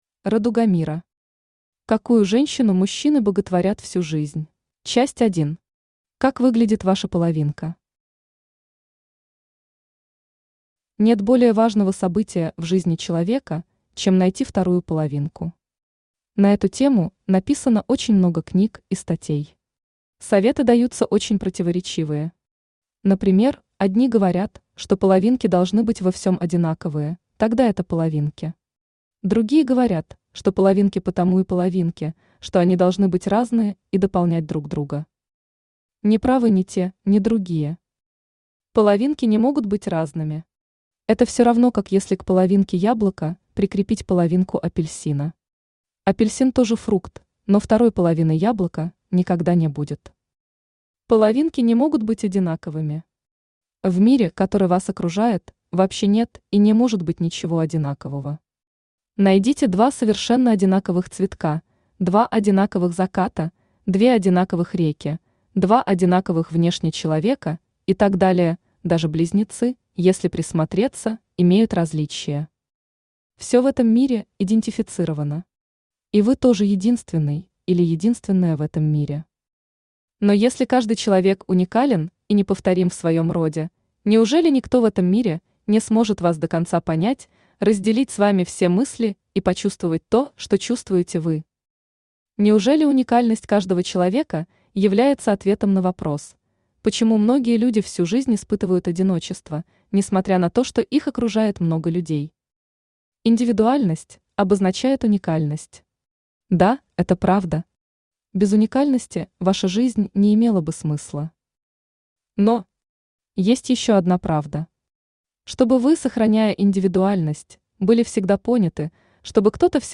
Аудиокнига Какую женщину мужчины боготворят всю жизнь | Библиотека аудиокниг
Aудиокнига Какую женщину мужчины боготворят всю жизнь Автор Радугамира Читает аудиокнигу Авточтец ЛитРес.